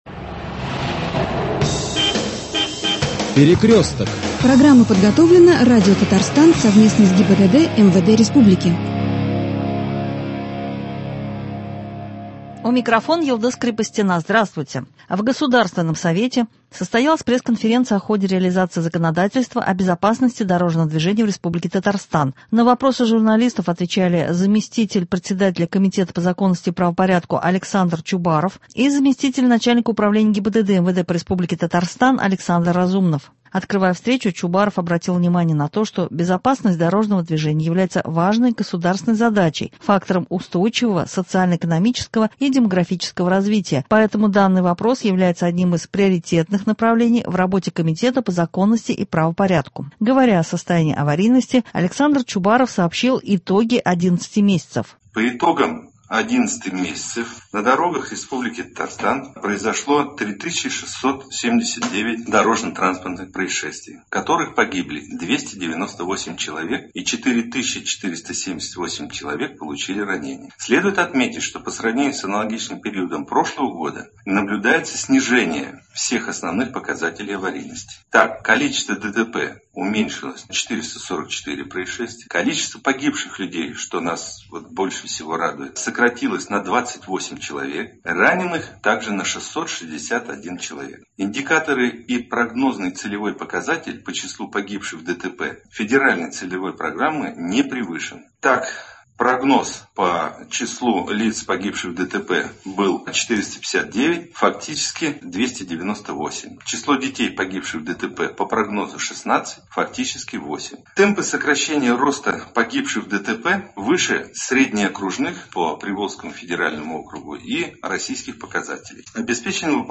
В Государственном Совете состоялась пресс-конференция о ходе реализации законодательства о безопасности дорожного движения в Республике Татарстан. На вопросы представителей республиканских СМИ ответили заместитель председателя Комитета по законности и правопорядку Александр Чубаров и заместитель начальника Управления ГИБДД МВД по Республике Татарстан Александр Разумнов.